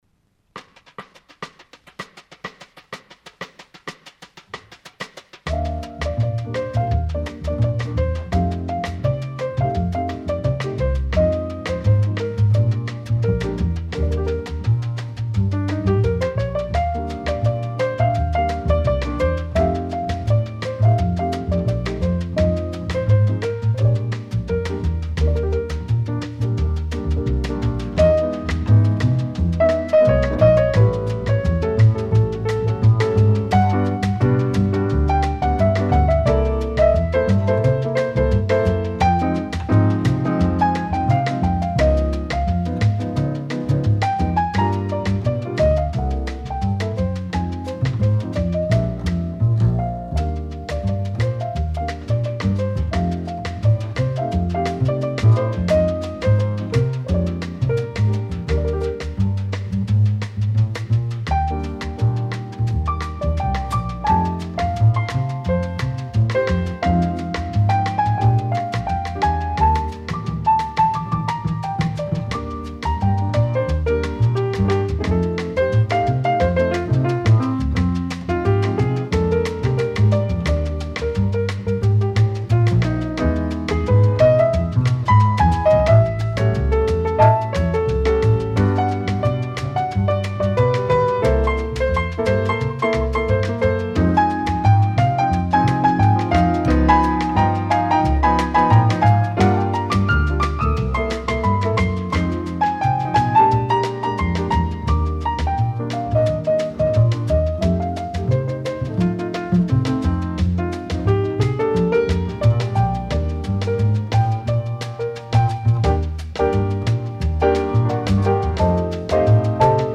Новые поступления СТАРОГО РАДИО. Инструментальная музыка советских и зарубежных композиторов (ч. 11-я).